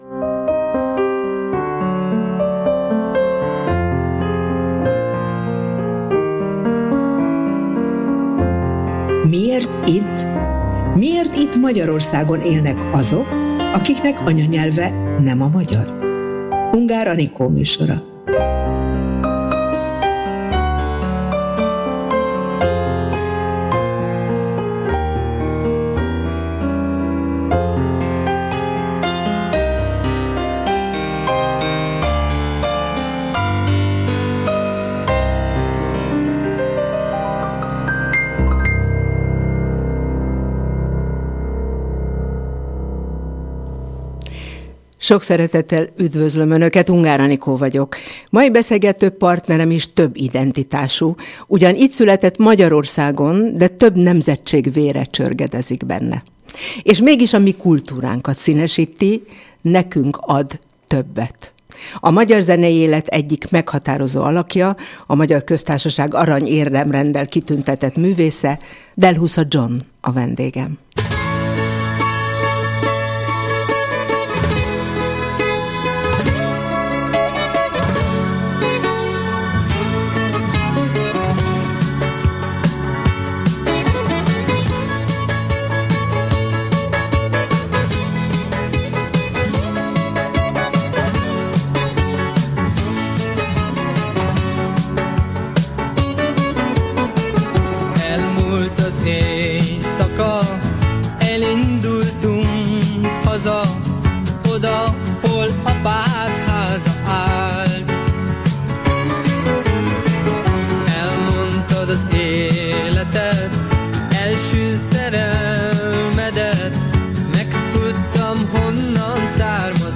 Ha jól odafigyelünk dalaira, balkáni gyökerei egész zenei életművét átitatják. A beszélgetés legvégén belehallgathatunk az "Ébredj Albániám" c. dalba is, melyet Gjoni Albánia kommunizmus alóli felszabadulása alkalmából írt, édesapja emlékére.